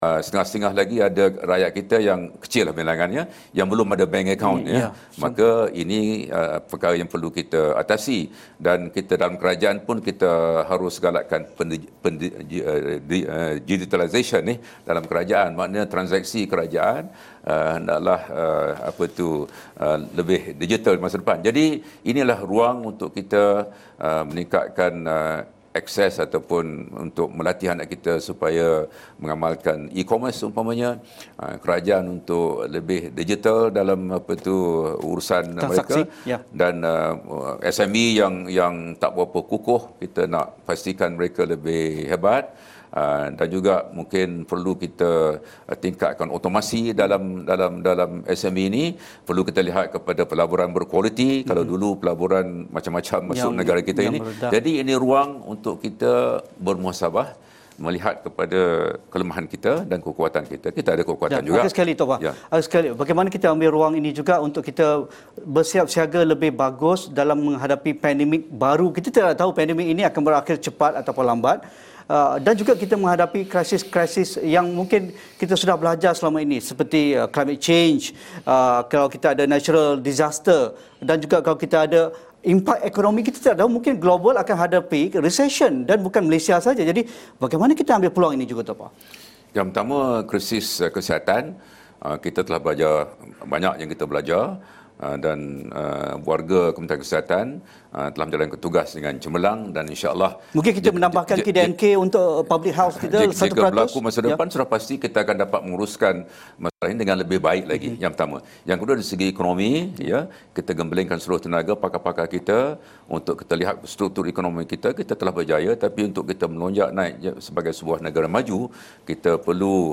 Bagaimana mendepani impak pandemik Covid-19 kepada rakyat dan ekonomi negara? Apa perancangan ketika melalui fasa pemulihan semula? Temu bual